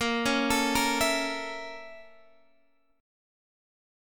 A#mM7b5 Chord